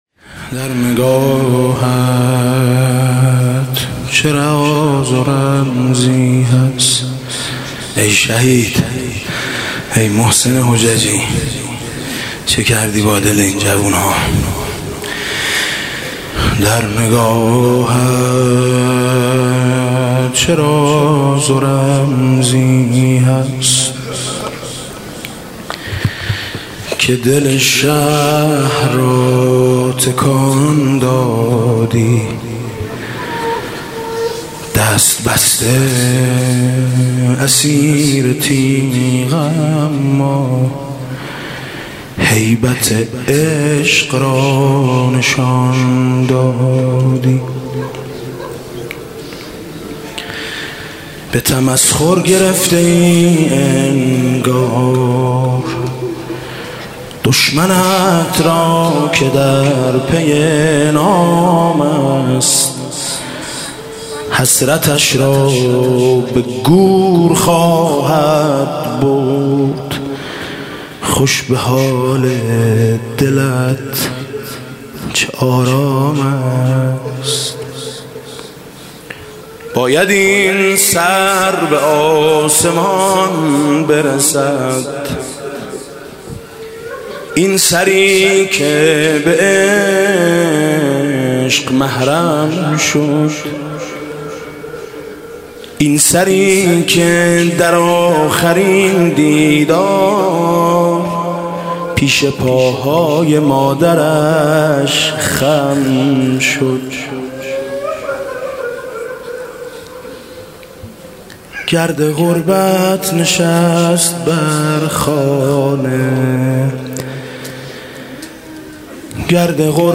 «محرم 1396» (شب پنجم) نجوا: در نگاهت چه راز و رمزی هست